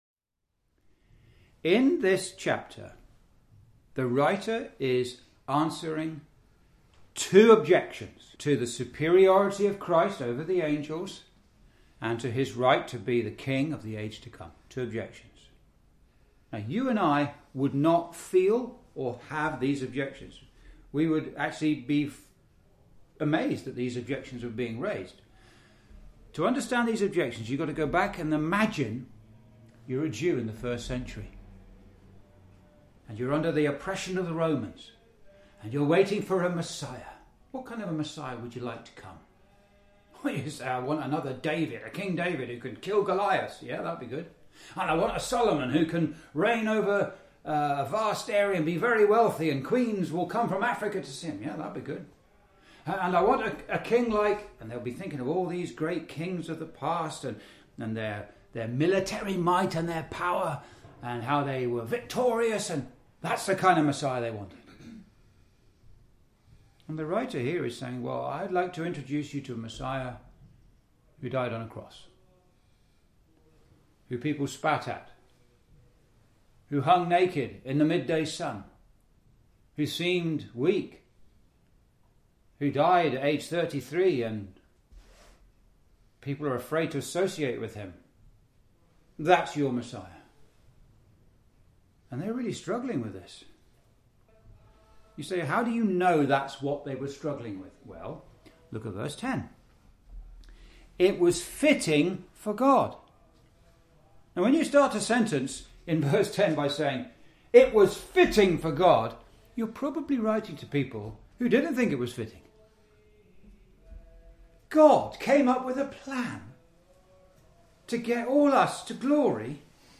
Verse by Verse Exposition